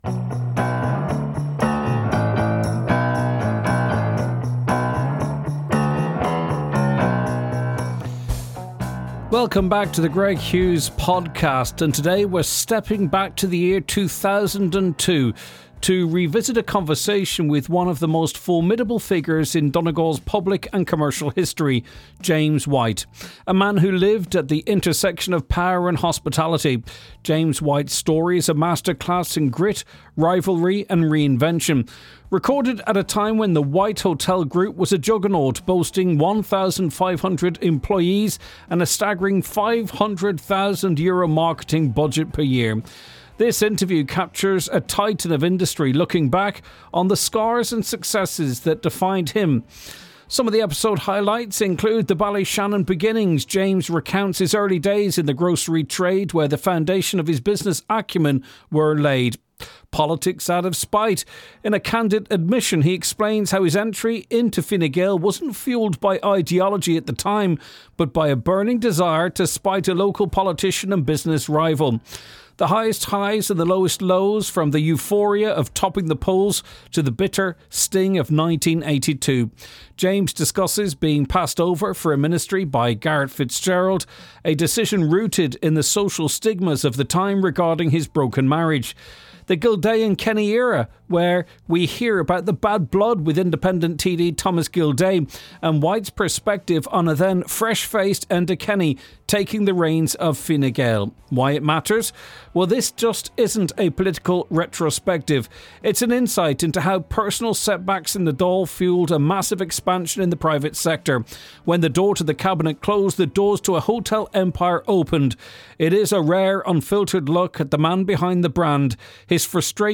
In this newly released archival recording, we step back over two decades to hear White in his own words: unfiltered, sharp, and remarkably candid about the friction that defined his life in both business and the Dáil.